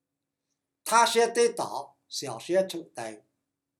51大树跌倒